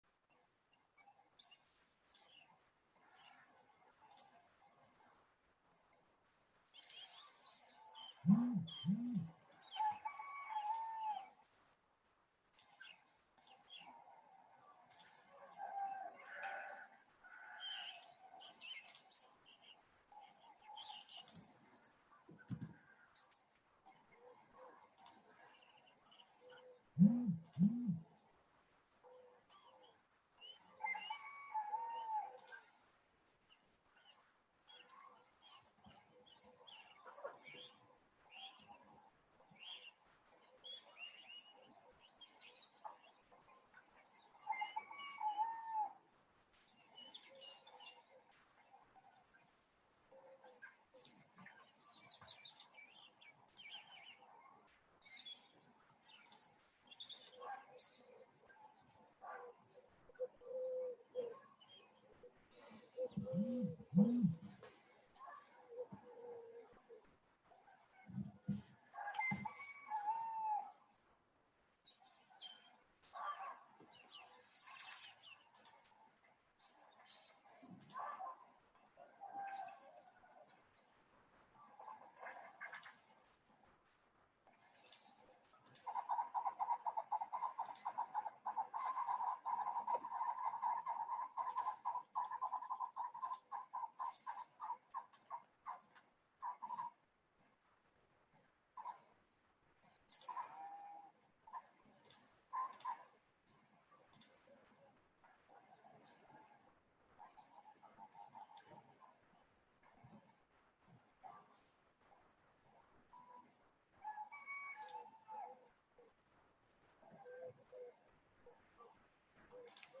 Пожертвования (онлайн-семинар, 2022)